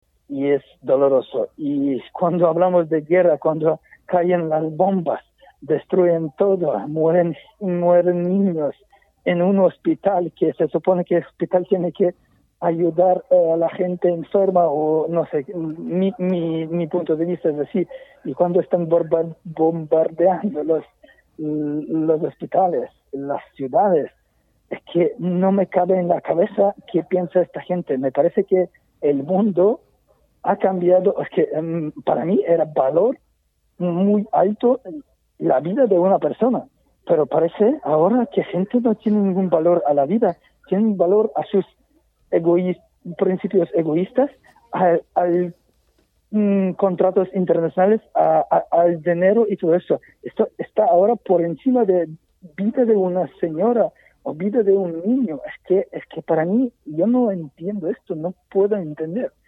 La escalada de violencia no había hecho más que empezar y multiplicarse desde que se realizara esta entrevista, vía teléfono móvil, en la tarde del jueves 10 de marzo.
Imposible poner palabras a tantos sentimientos, con temblor percibido en la voz.